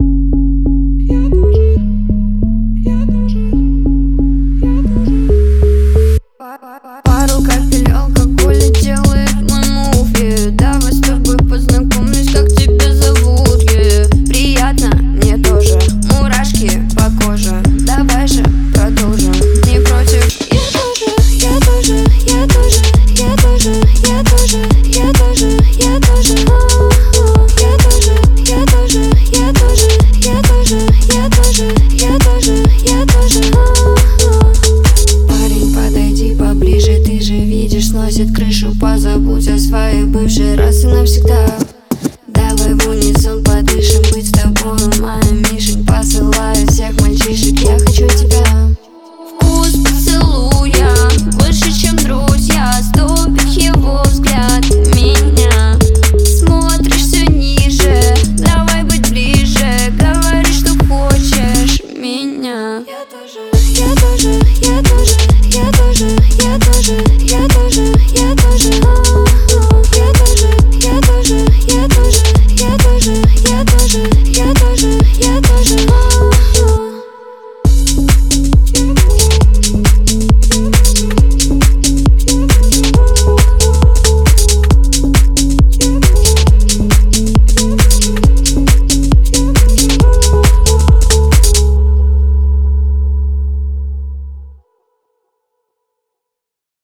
Жанр: Русские народные песни